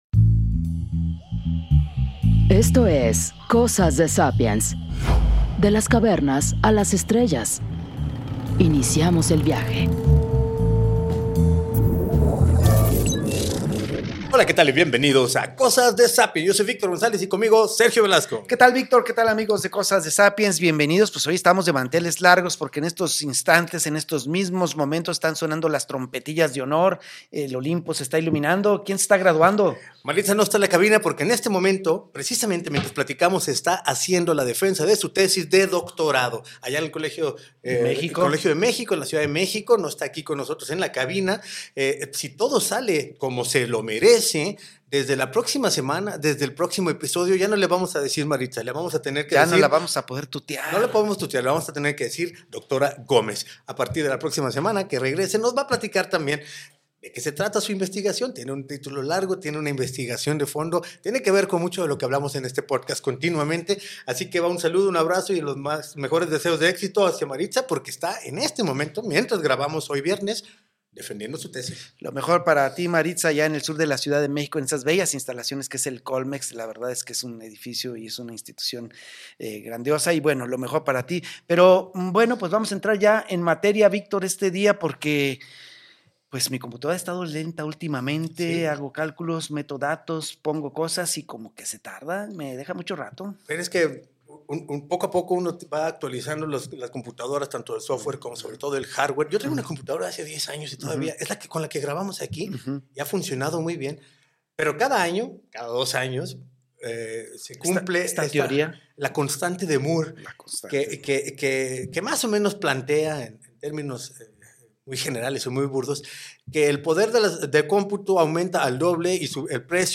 especialistas en computación cuántica para explicarnos.